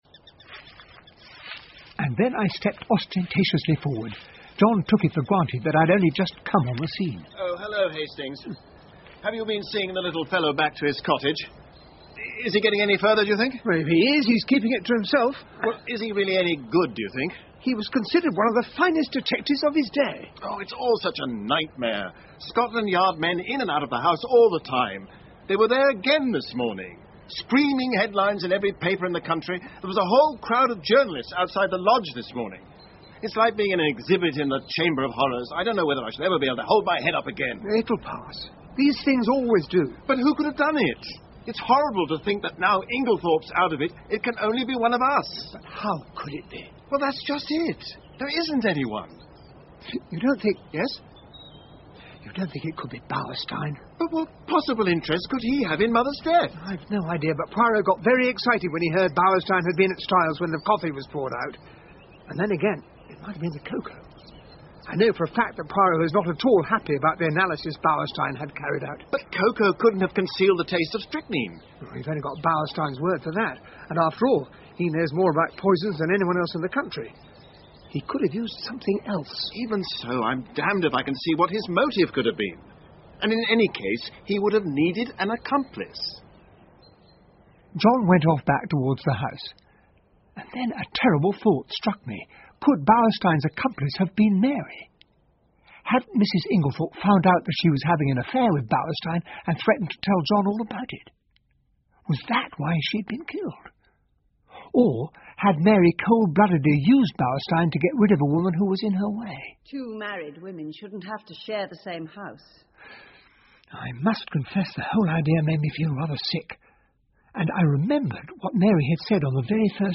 英文广播剧在线听 Agatha Christie - Mysterious Affair at Styles 16 听力文件下载—在线英语听力室